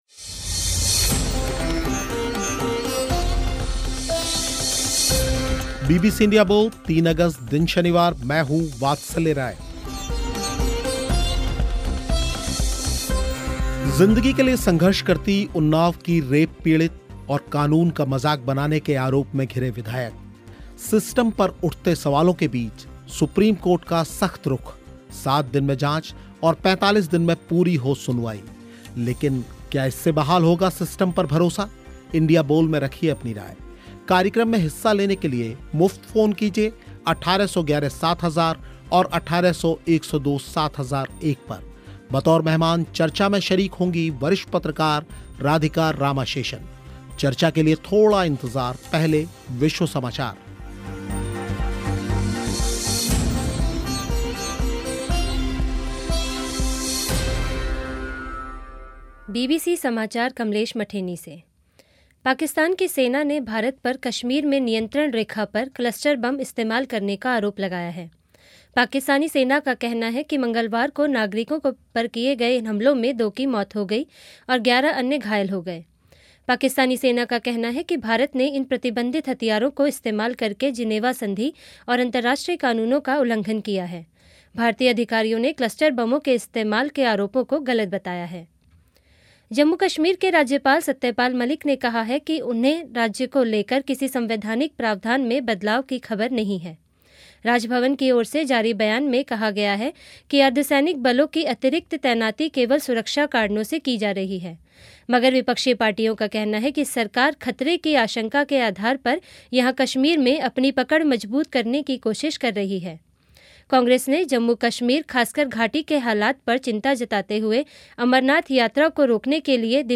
इसी मुद्दे पर इंडिया बोल में हुई चर्चा
श्रोताओं ने भी रखी अपनी राय